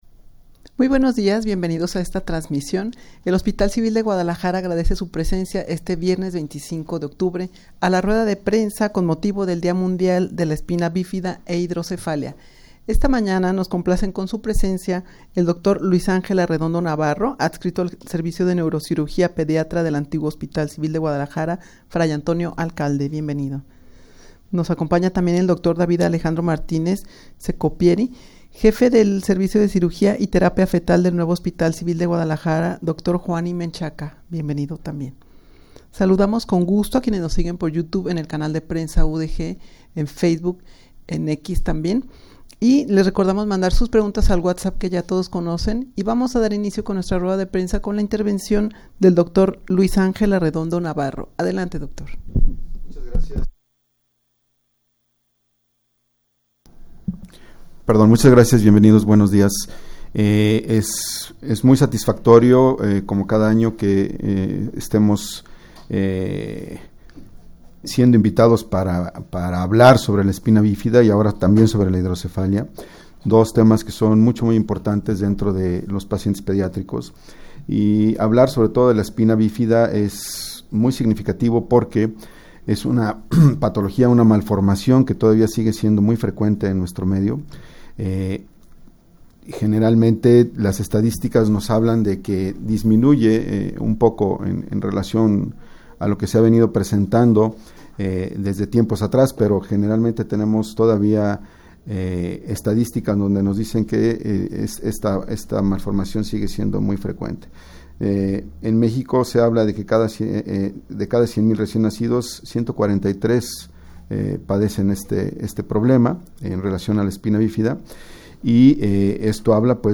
rueda-de-prensa-con-motivo-del-dia-mundial-de-la-espina-bifida-e-hidrocefalia.mp3